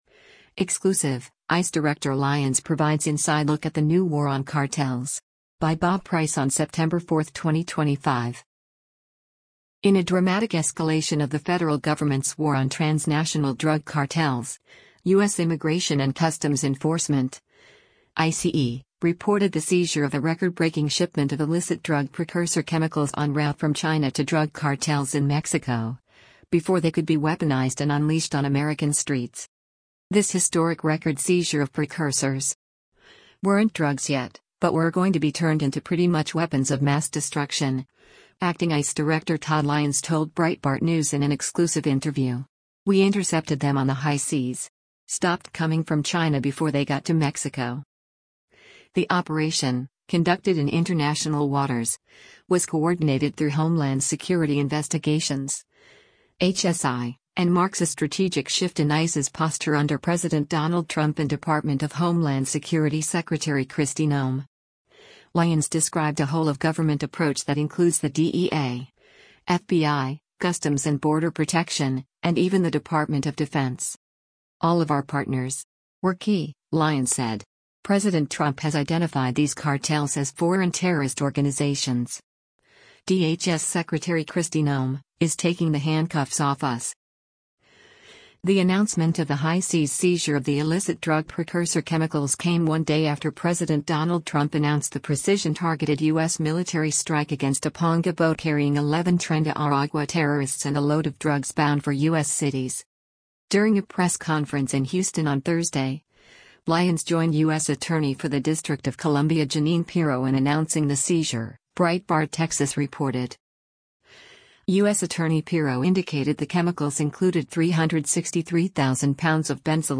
Editor’s Note: This is part one of a three-part exclusive interview with Acting ICE Director Todd Lyons.